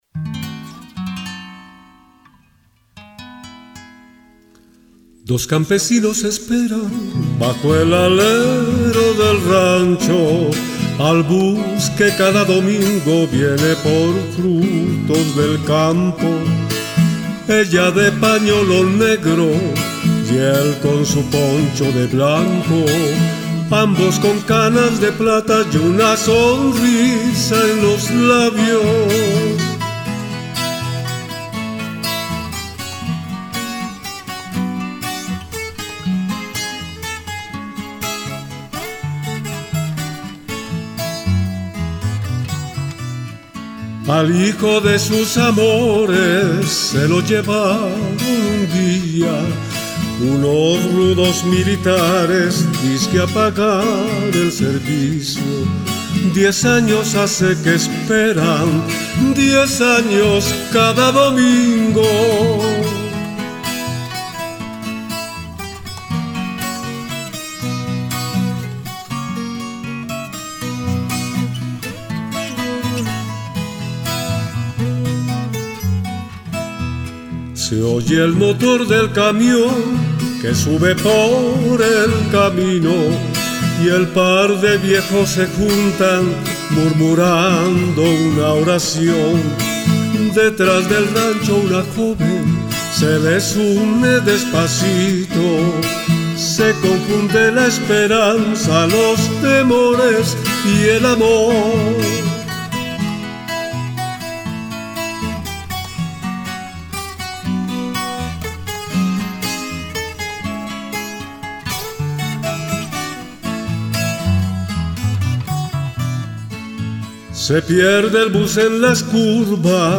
Canción
voz y guitarra.
Tiple.